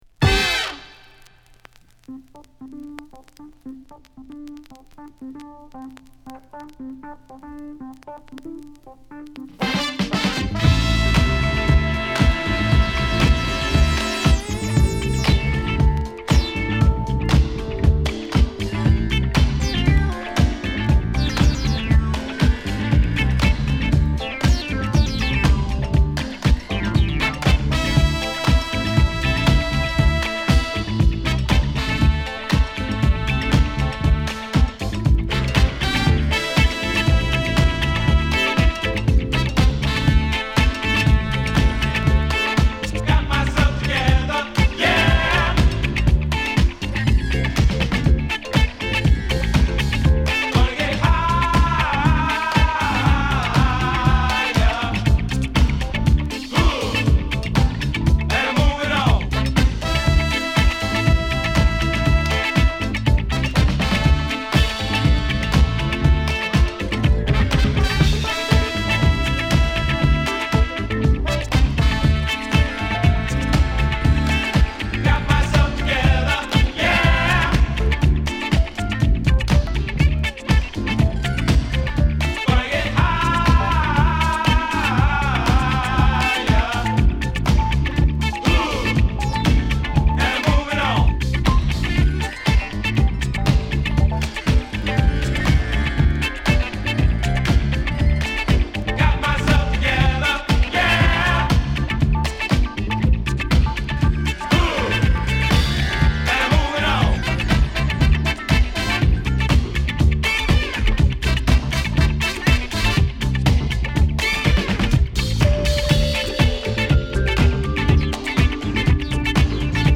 疾走感あるビートに熱血のホーンやヴォーカルに飛び交うシンセが絡む、文句無しのクラシック・チューン！